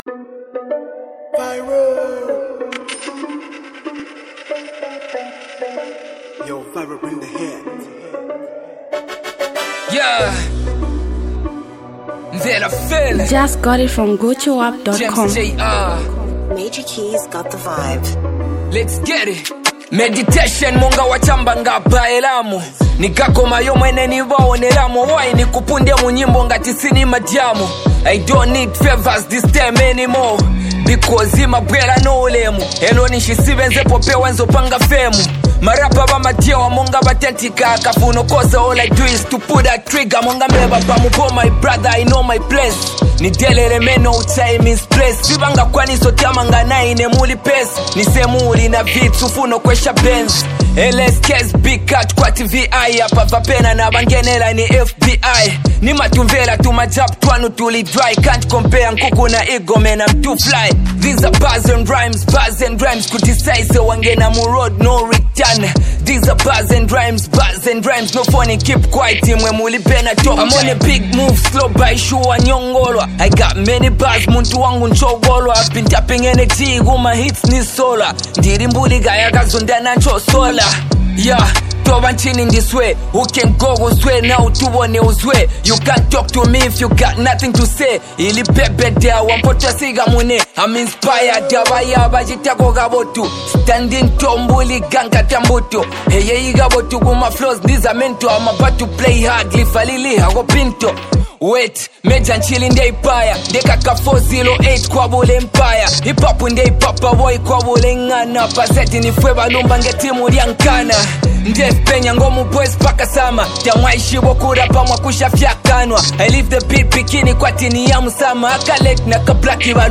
Zambian Mp3 Music
powerful lyrical freestyle